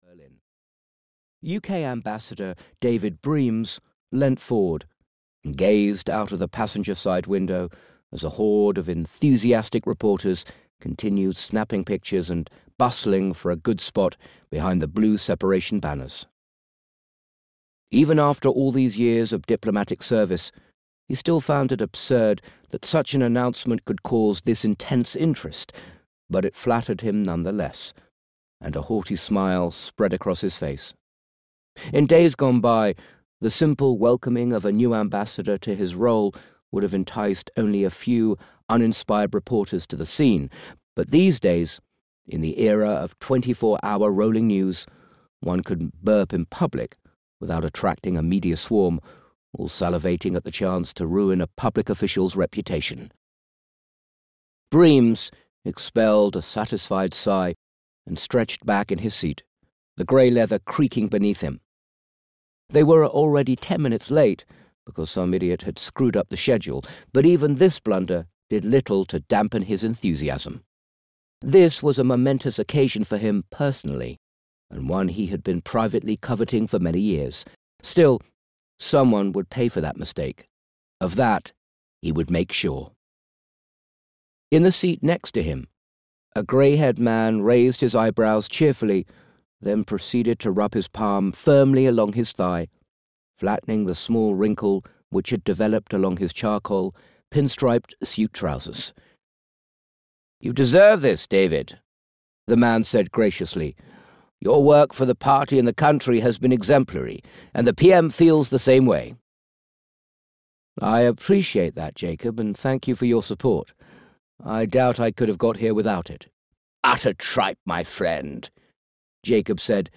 Project Icarus (EN) audiokniha
Ukázka z knihy